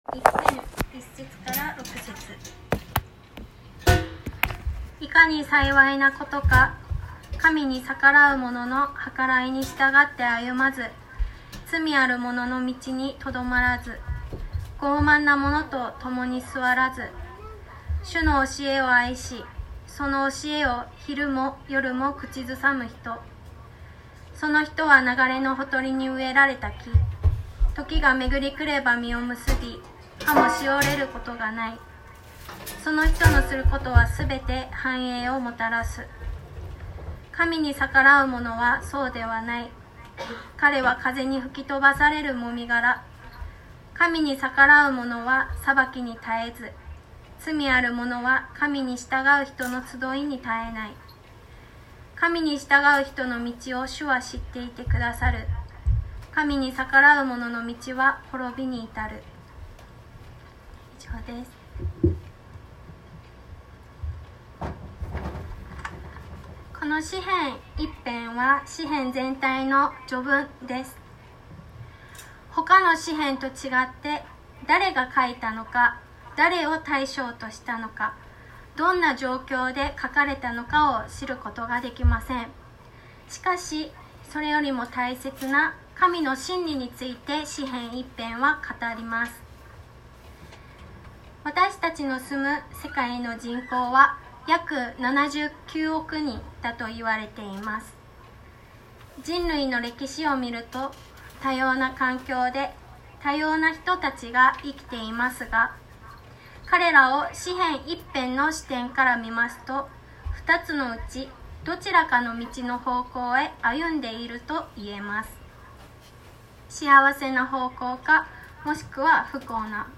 説教アーカイブ。
日曜 夕方の礼拝